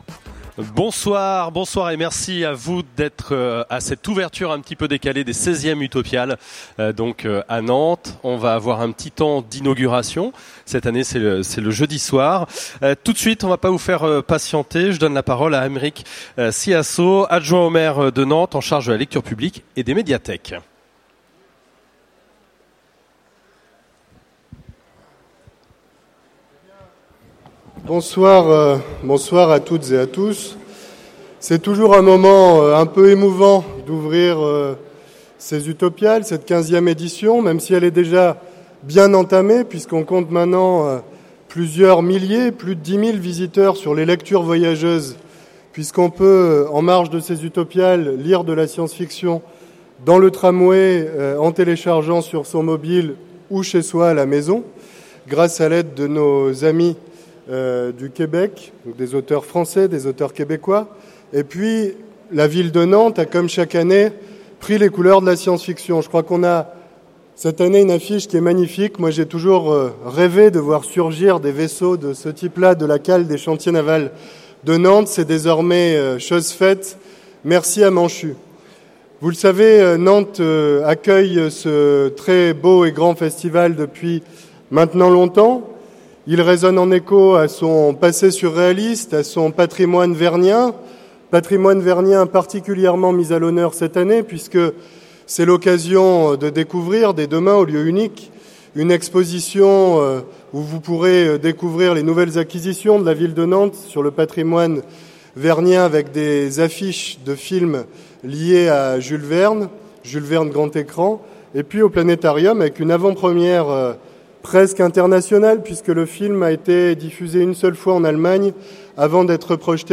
Utopiales 2015 : Discours inauguraux